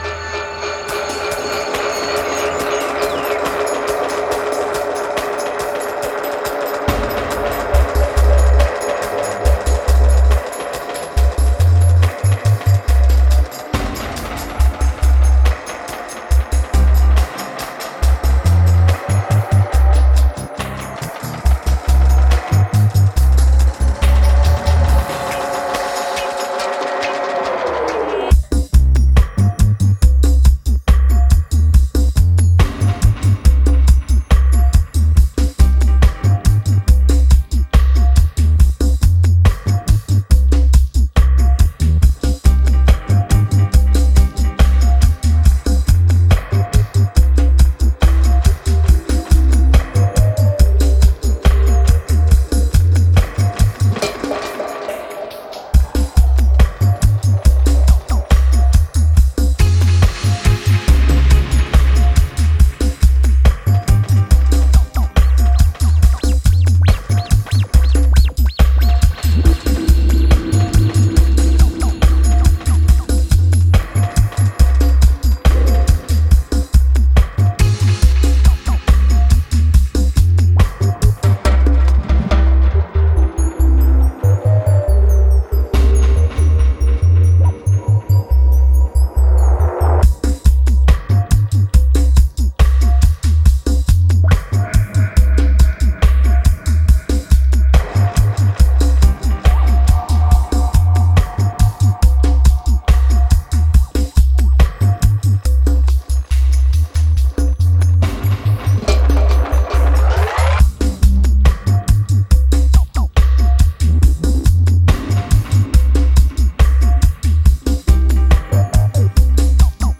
Genre: Dub.